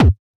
Kick_Bouncy.wav